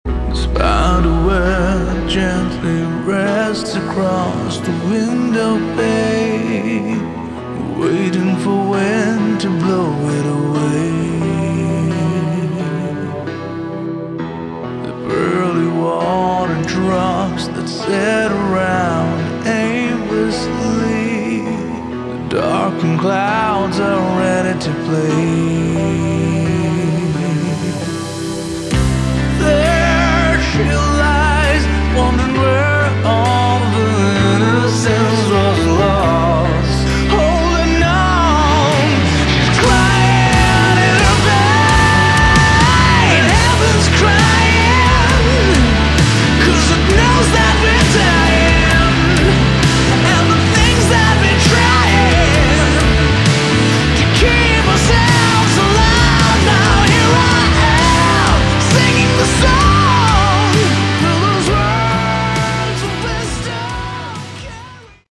Category: Hard Rock
vocals, rhythm guitars
bass, keyboards
lead guitars
drums